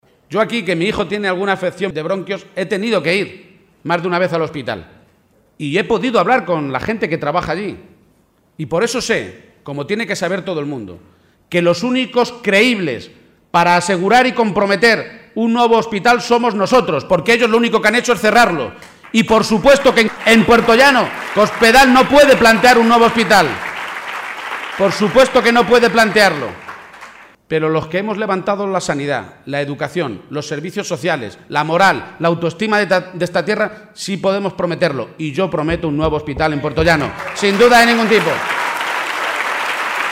Lo ha hecho en un acto ante más de 500 personas, en el que ha estado por la Presidenta de la Junta de Andalucía, Susana Díaz.